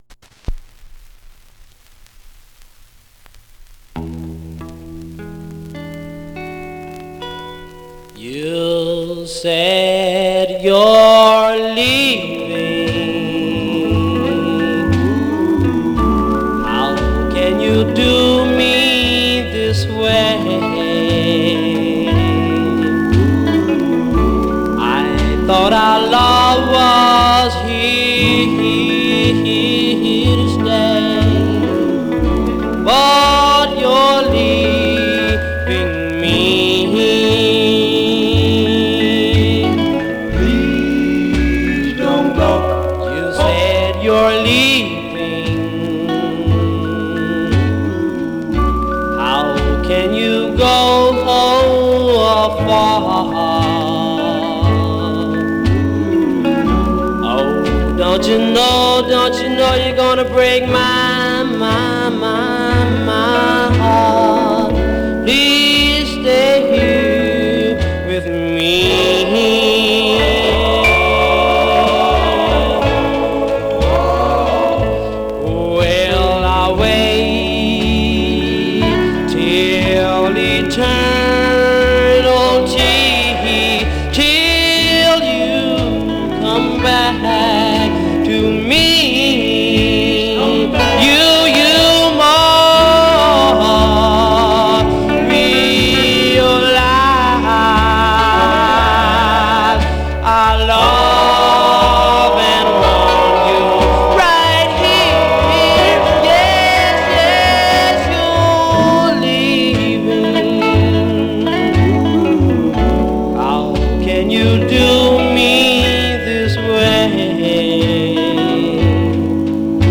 Stereo/mono Mono
Vocal Group